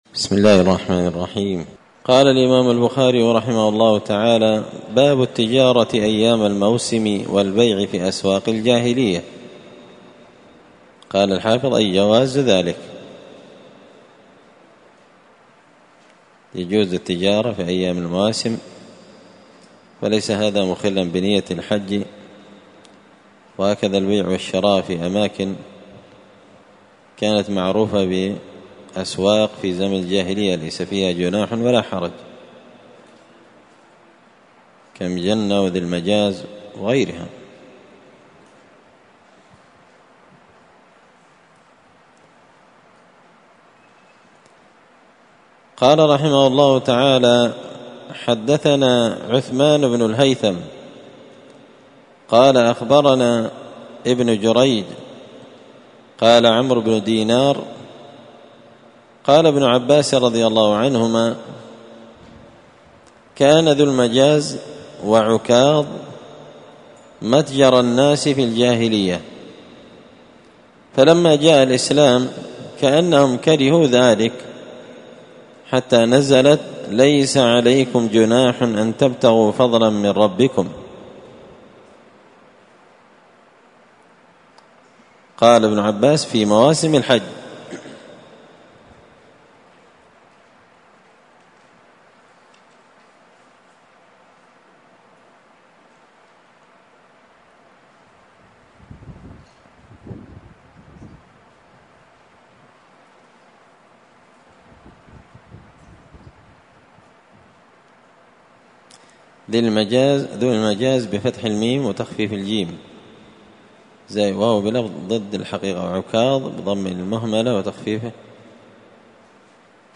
مسجد الفرقان قشن المهرة اليمن